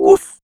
WOOFFM.wav